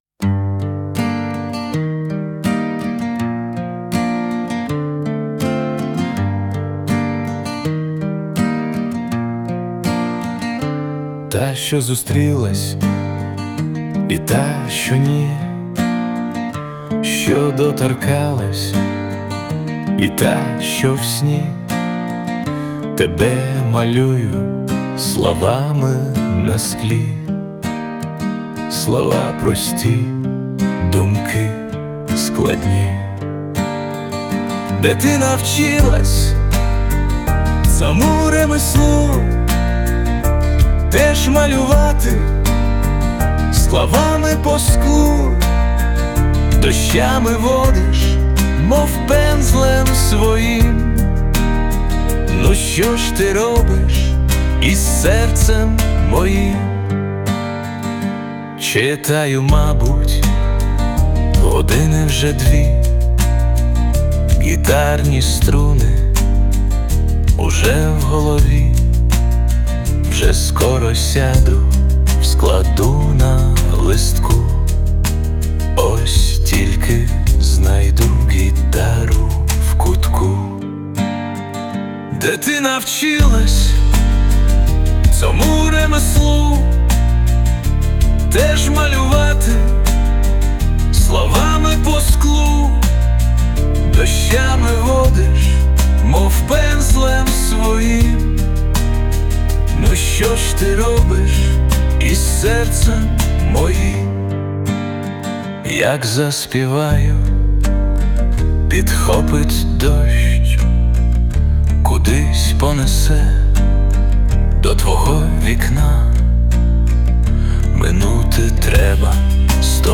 Присутня допомога SUNO
СТИЛЬОВІ ЖАНРИ: Ліричний
Чудова мелодія, гарне виконання та зворушливі рядки!